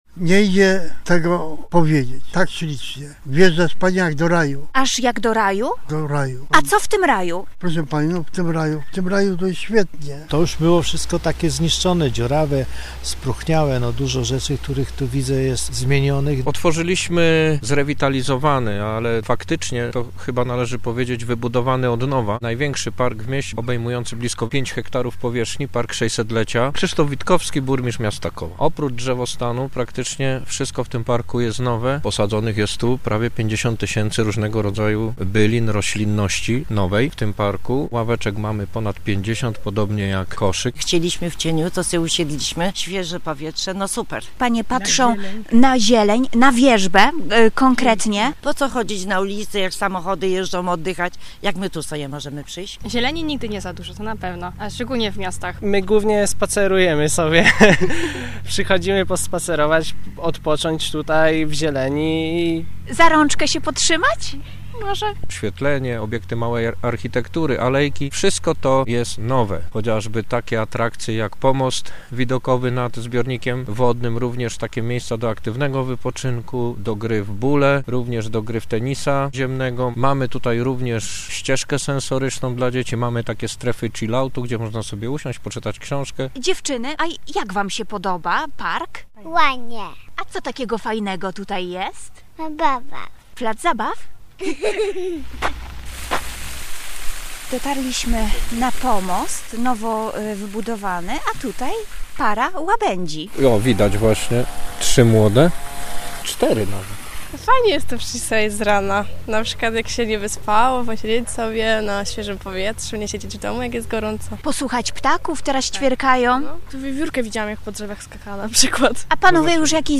- wyjaśnia Krzysztof Witkowski.
-mówią mieszkańcy.